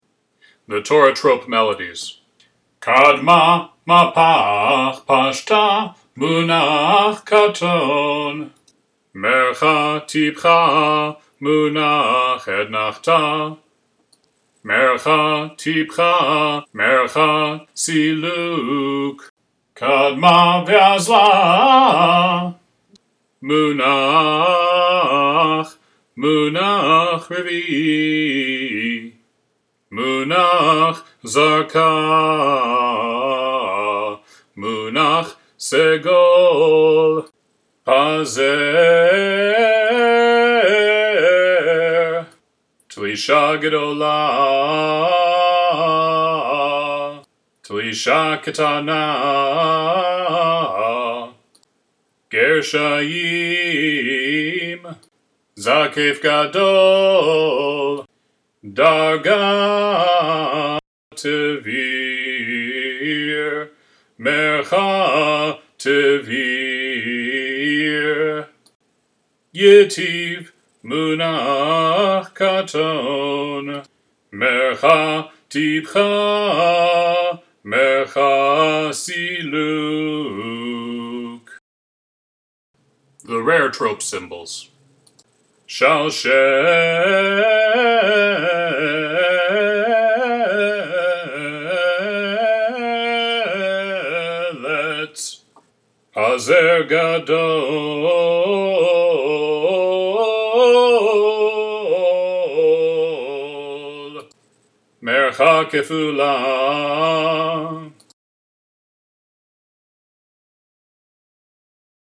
All Torah trope (single file):